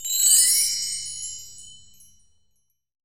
FRBELLTRE2-S.WAV